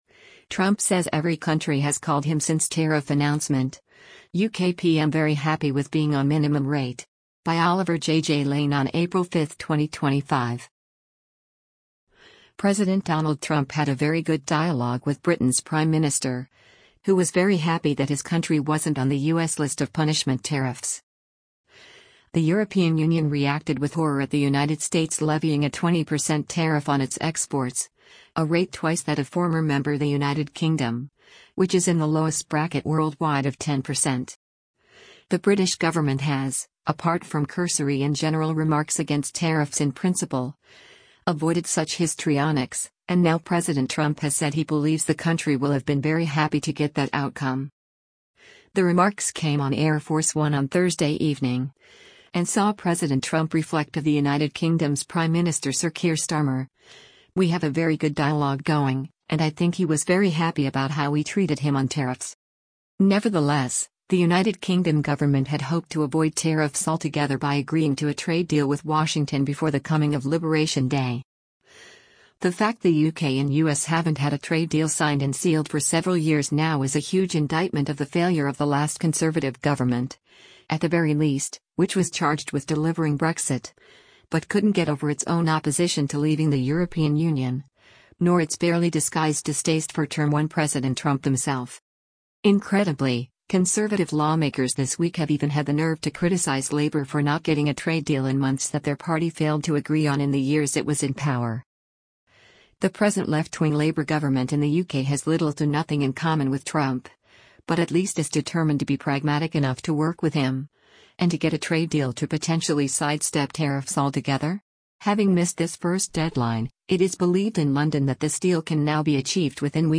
WASHINGTON, DC - APRIL 3: U.S. President Donald Trump speaks to members of the media befor
The remarks came on Air Force One on Thursday evening, and saw President Trump reflect of the United Kingdom’s Prime Minister Sir Keir Starmer: “…we have a very good dialogue going, and I think he was very happy about how we treated him on tariffs”.